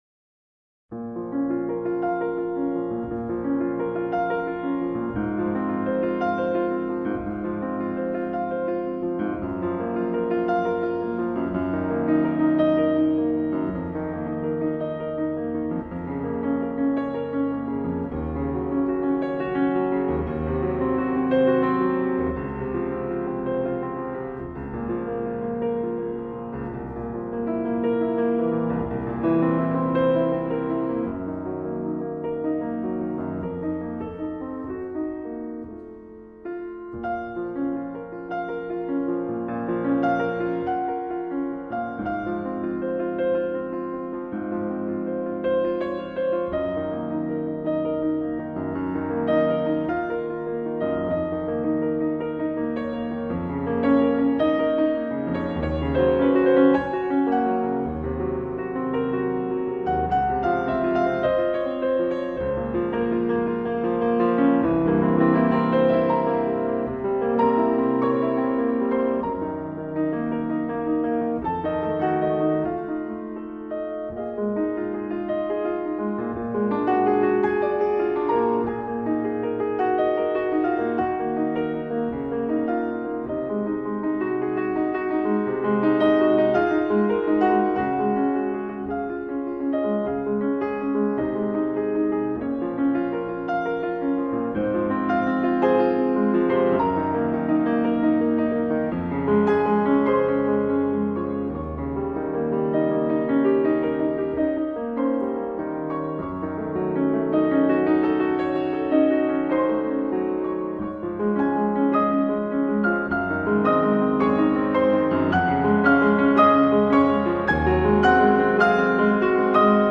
Incontro con Geoff Westley (1./2)